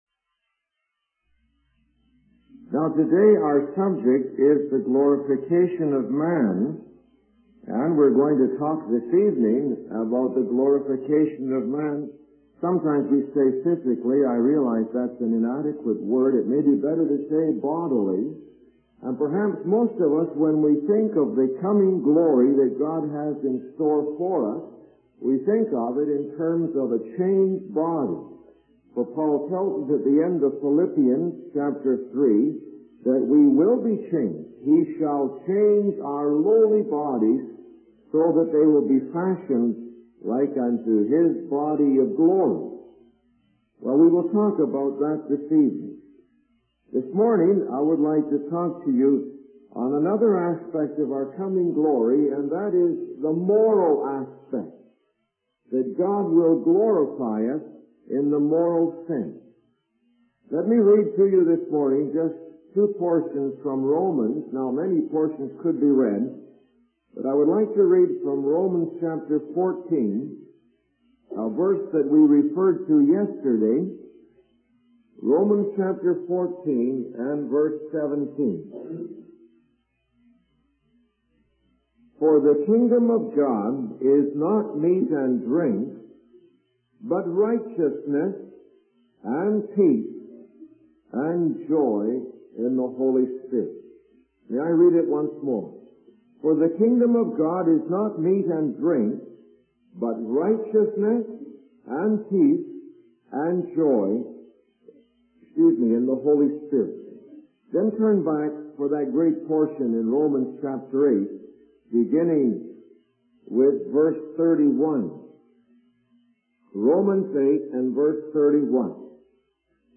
In this sermon, the speaker discusses the relationship between truth and freedom, using the example of the Dominican Republic's national flag. He emphasizes that knowing the truth can set us free, as Jesus said in John's Gospel. The speaker also shares personal experiences of how encountering the truth in the Bible has changed his thinking and corrected his erroneous thoughts.